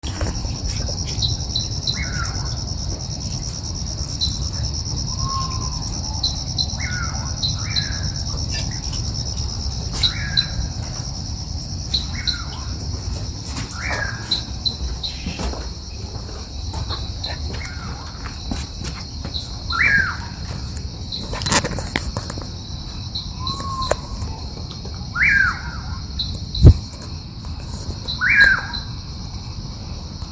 Urutaú Común (Nyctibius griseus)
Nombre en inglés: Common Potoo
Localidad o área protegida: Reserva Privada San Sebastián de la Selva
Condición: Silvestre
Certeza: Observada, Vocalización Grabada